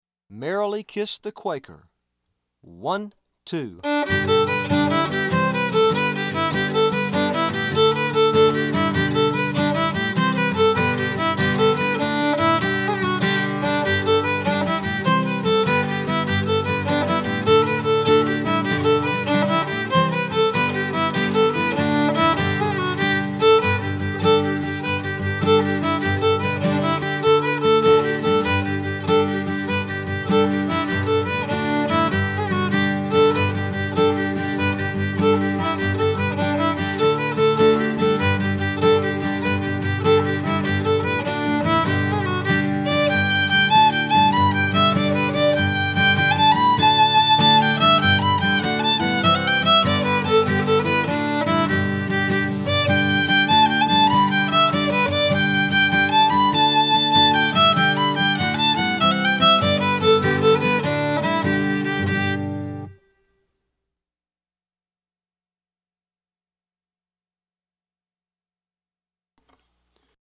DIGITAL SHEET MUSIC - FIDDLE SOLO
Fiddle Solo, Traditional, Irish/American Jig